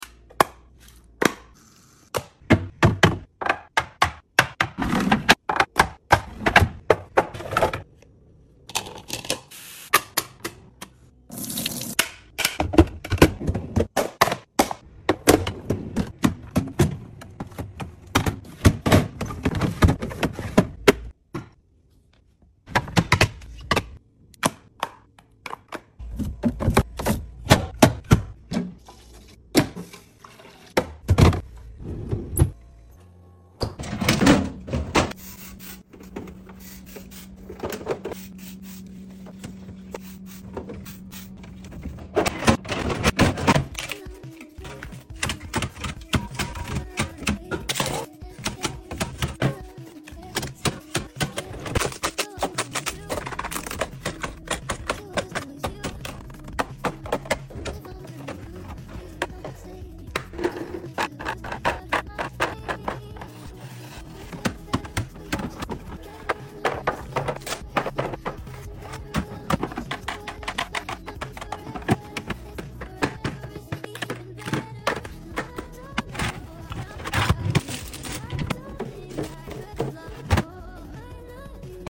fridge restock asmr ♡ sound effects free download